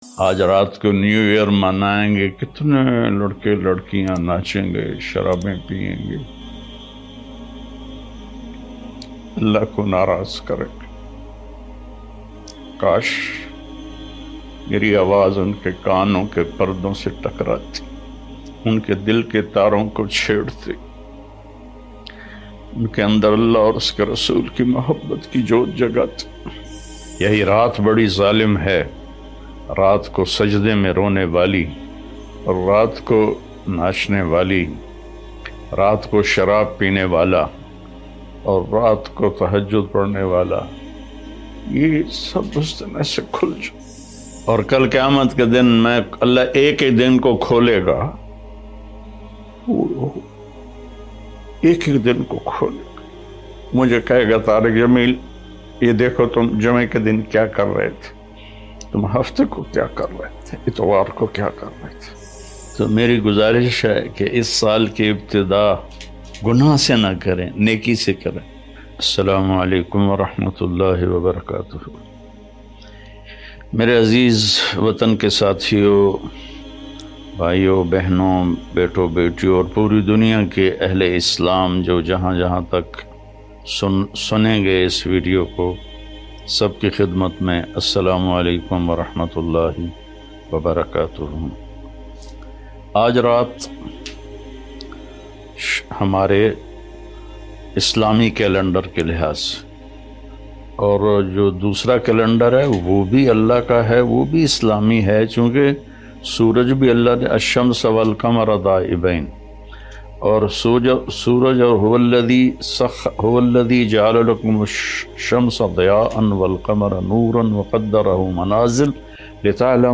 New Year 2023 – Maulana Tariq Jameel Latest Bayan: 31 December 2022 Night